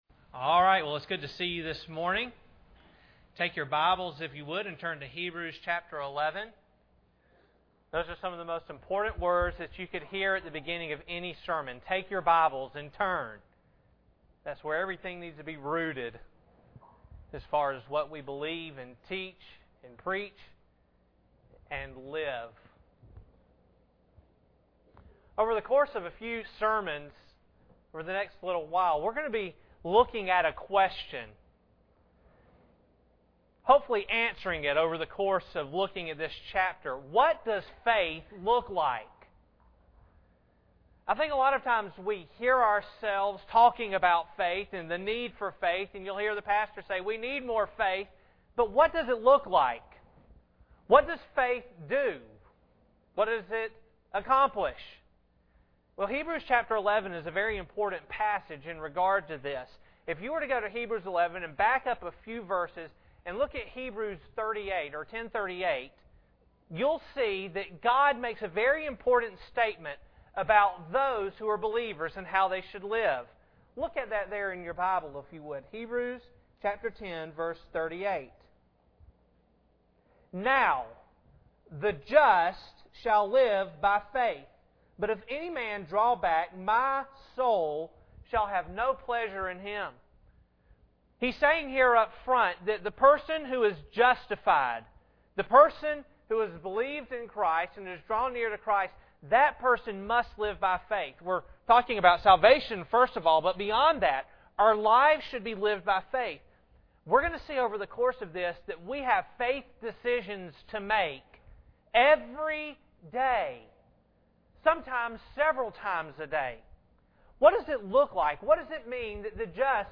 Hebrews 10:38 Service Type: Sunday Morning Bible Text